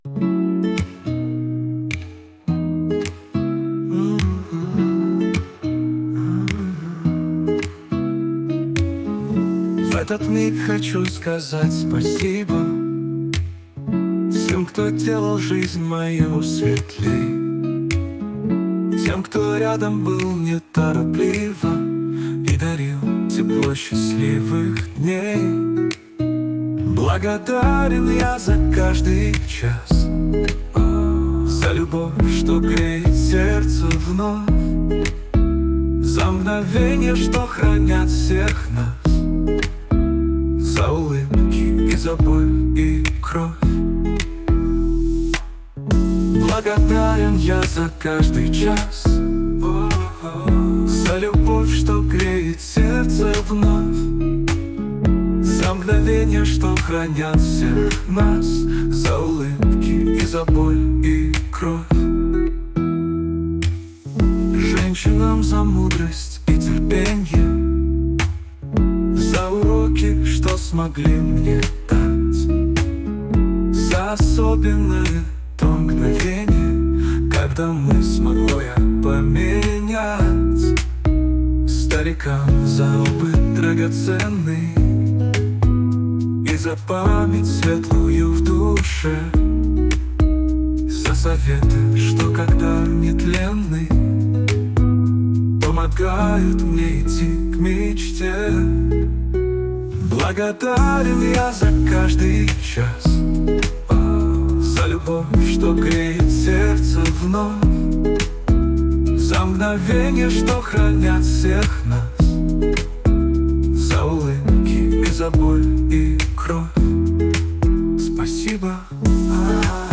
Жанр: Hypnogogical